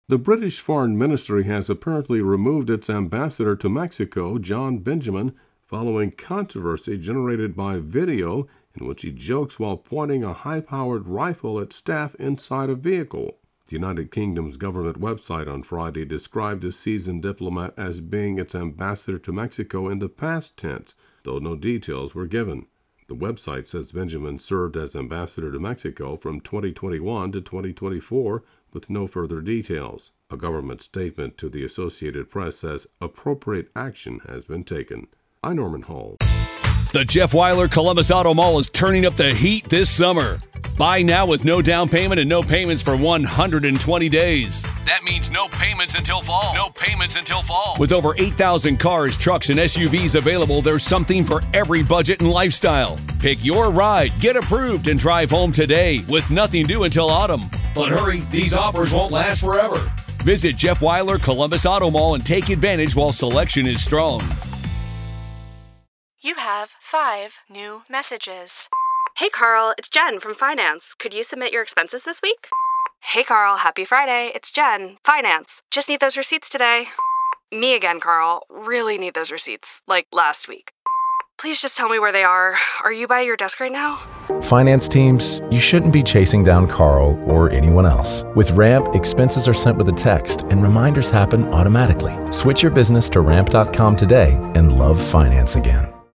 reports on a gun incident involving the British ambassador to Mexico.